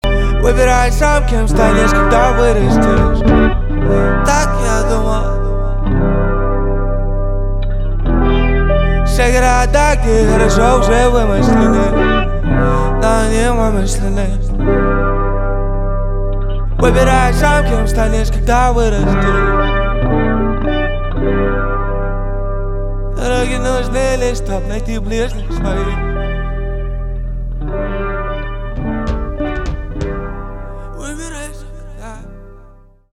альтернатива
гитара , чувственные
барабаны